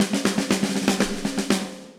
AM_MiliSnareC_120-01.wav